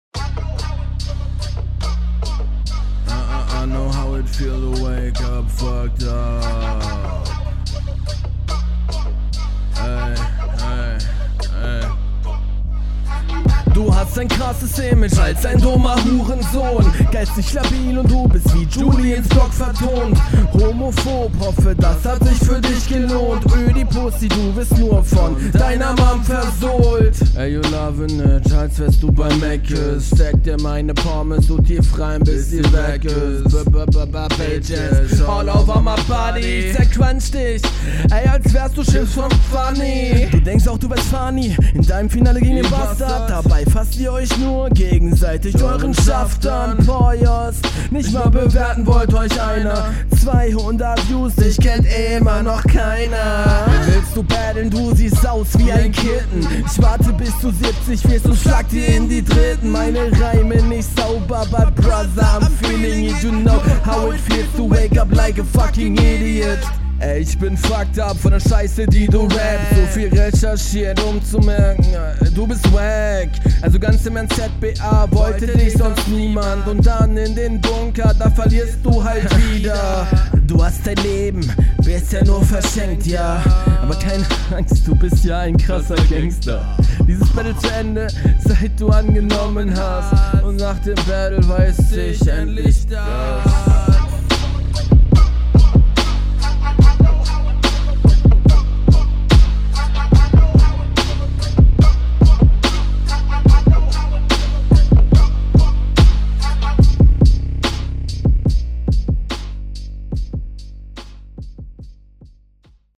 Uhh der Beat ist geil.
Geiler Einstieg sicker Flow!